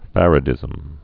(fărə-dĭzəm)